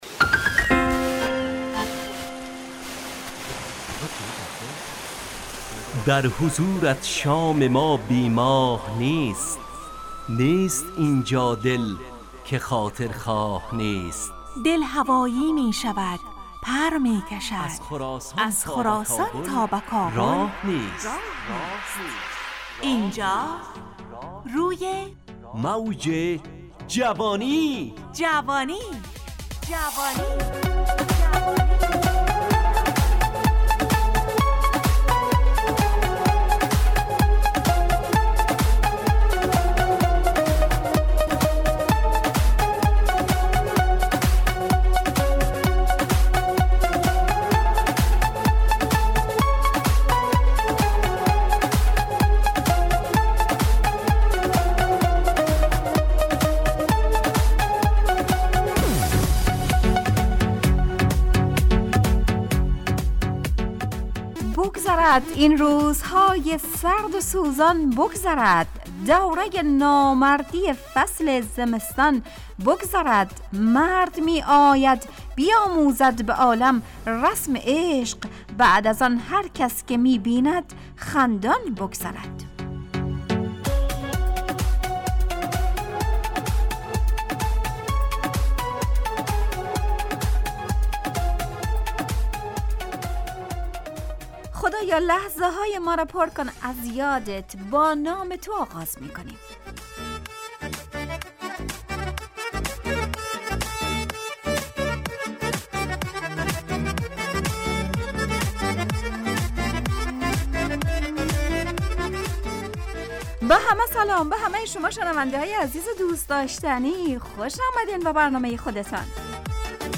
روی موج جوانی، برنامه شادو عصرانه رادیودری.
همراه با ترانه و موسیقی مدت برنامه 55 دقیقه . بحث محوری این هفته (مرد میدان) تهیه کننده